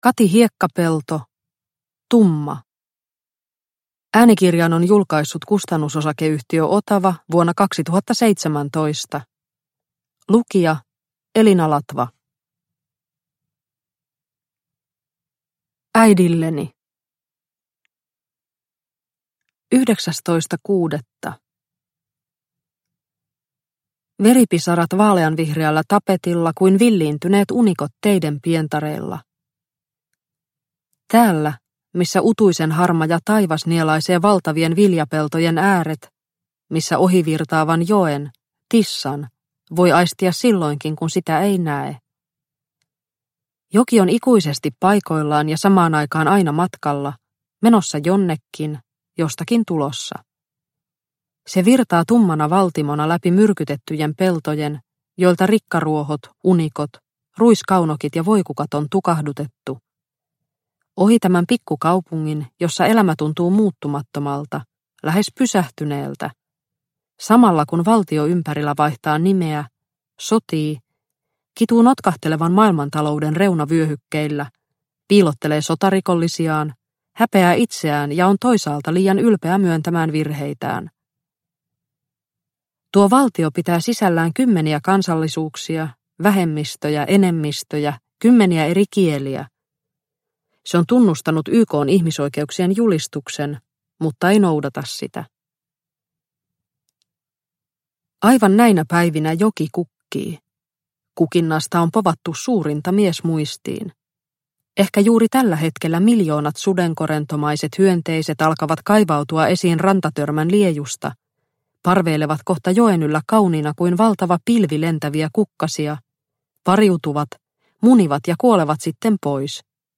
Tumma – Ljudbok – Laddas ner